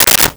Switchboard Telephone Receiver Down 01
Switchboard Telephone Receiver Down 01.wav